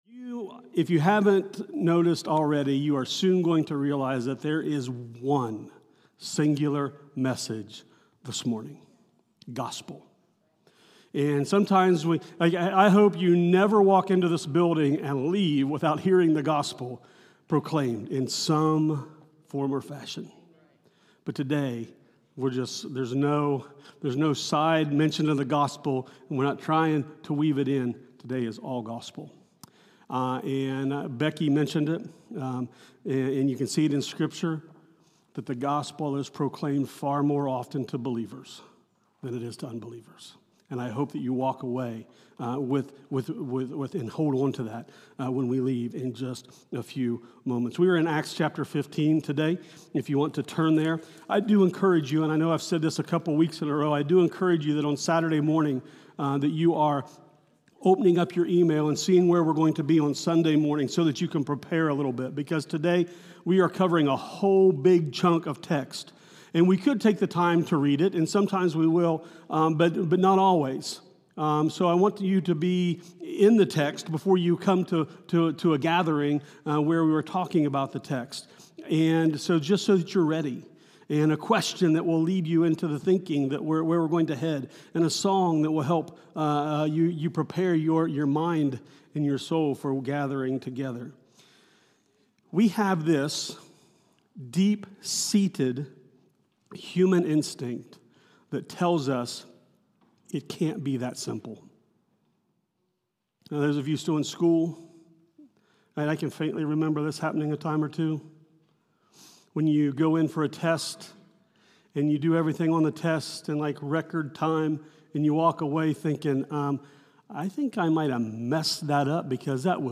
This weeks’ message is “No Other Gospel” from Acts 15.